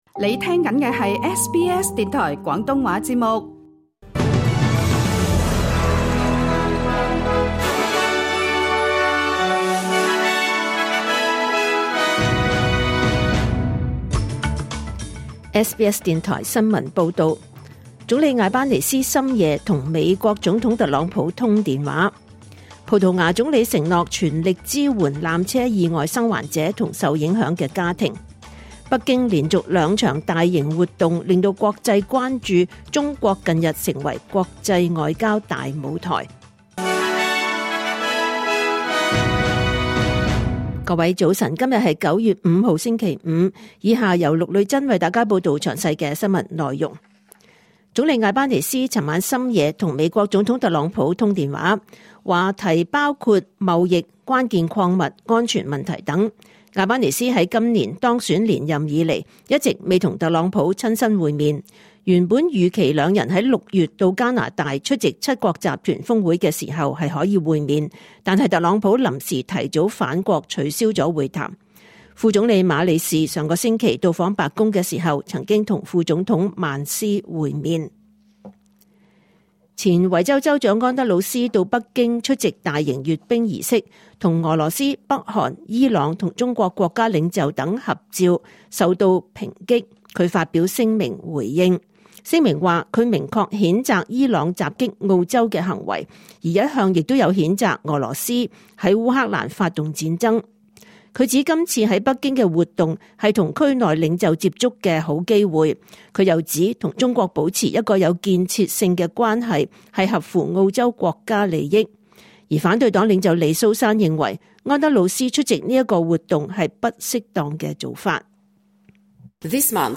2025年9月5日 SBS 廣東話節目九點半新聞報道。